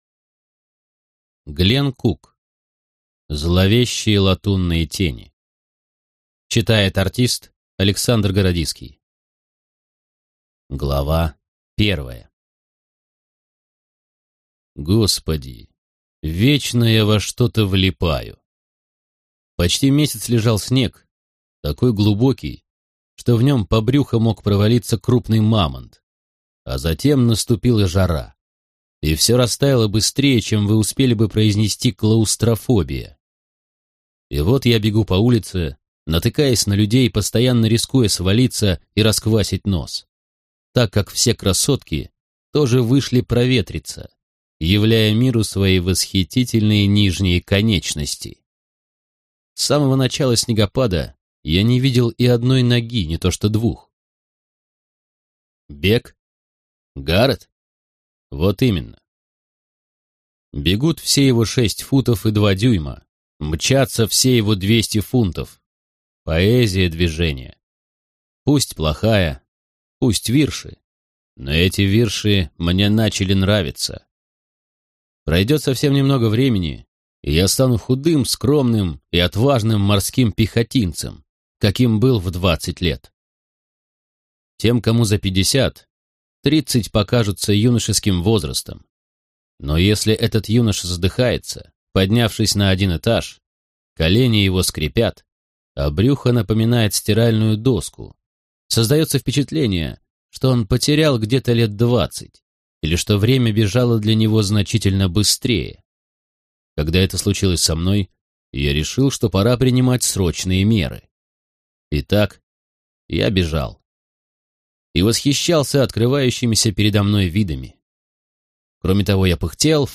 Аудиокнига Зловещие латунные тени | Библиотека аудиокниг